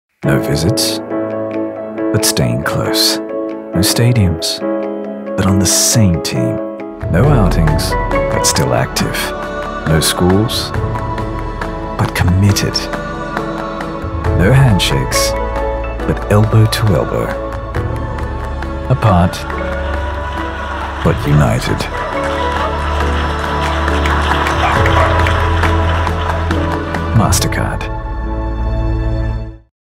Male
Television Spots
Words that describe my voice are Deep, Tenor, Credible.